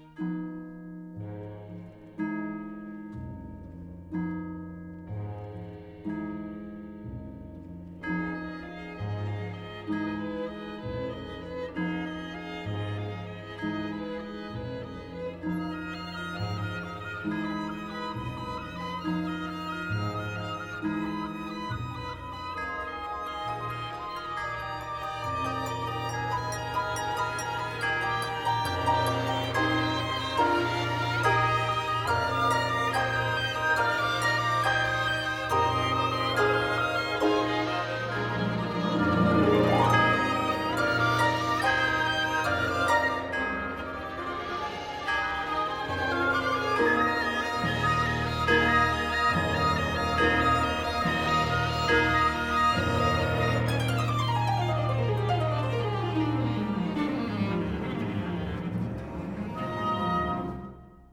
俄國管弦樂名作 / 室內樂改編版本
這個室內樂版本非常棒!